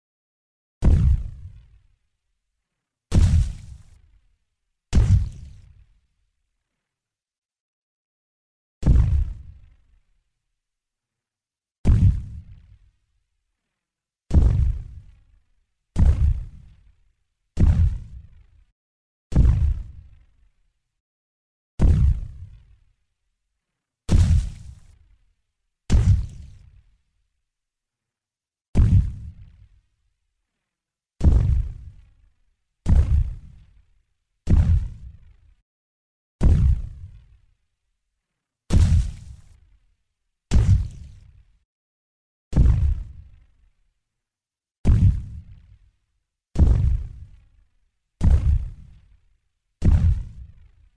Звуки шагов, бега
Тяжелые шаги и гулкий топот гиганта, чудовища, титана, древнего динозавра